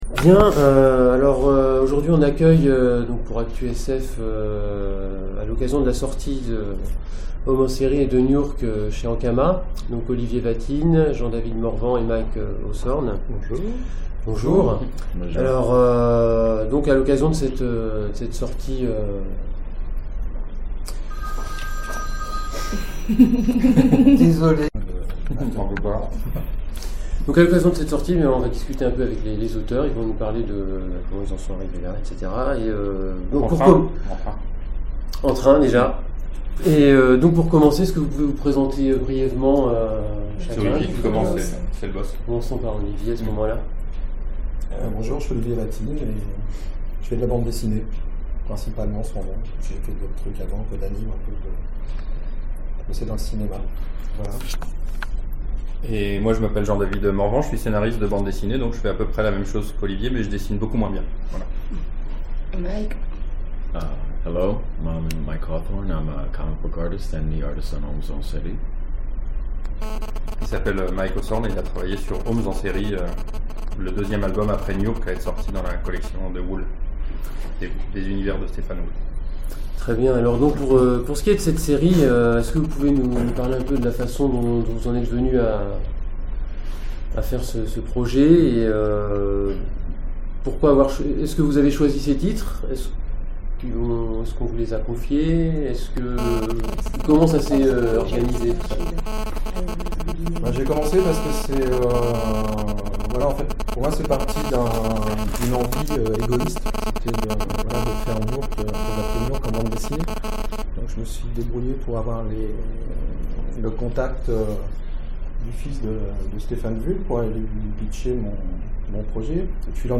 Interview autour de Stefan Wul en BD